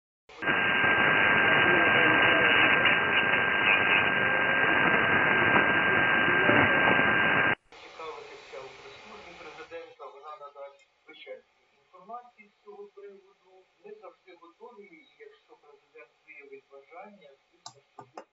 Работу фильтров смотреть лучше не на сигнале, а на шумах эфира.